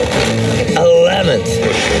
120BPMRAD2-R.wav